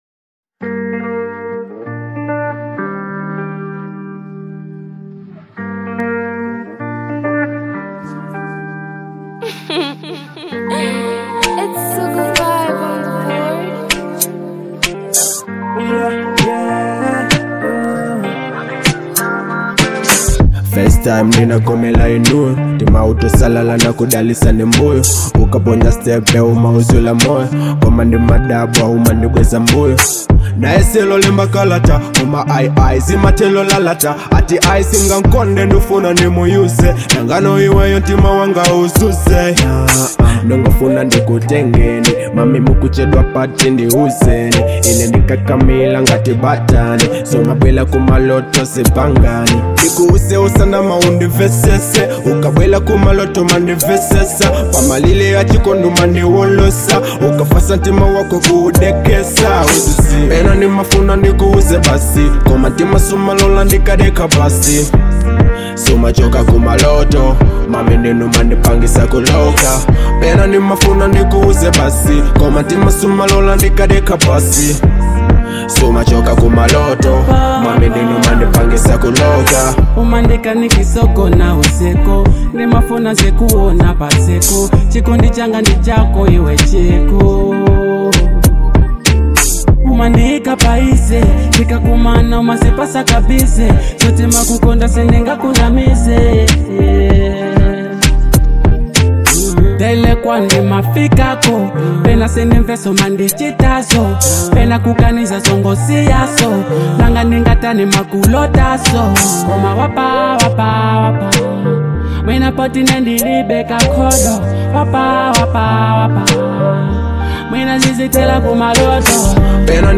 Genre : Afro Dancehall
is a catchy, melodic trap track
delivering smooth vibes and rhythmic energy.